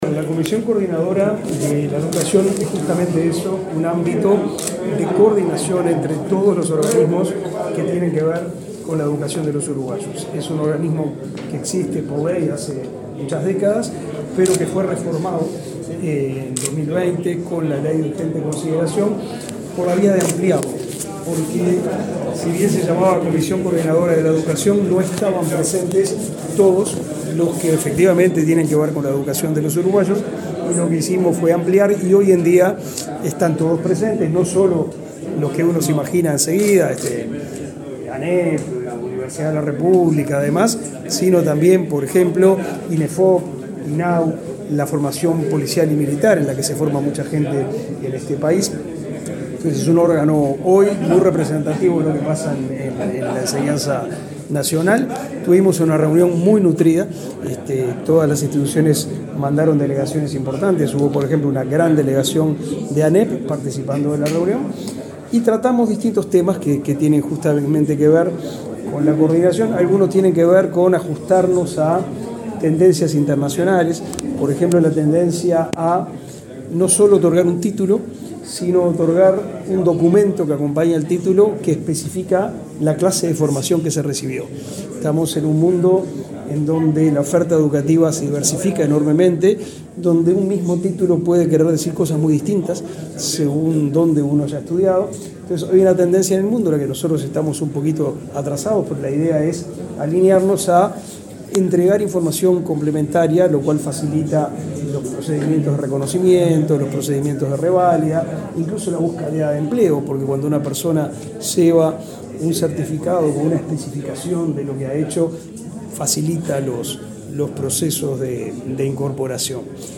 Declaraciones del ministro de Educación y Cultura, Pablo da Silveira
Declaraciones del ministro de Educación y Cultura, Pablo da Silveira 24/07/2023 Compartir Facebook X Copiar enlace WhatsApp LinkedIn Este lunes 24, el ministro de Educación y Cultura, Pablo da Silveira, participó en una sesión de la Comisión Coordinadora Nacional de Educación y luego dialogó con la prensa.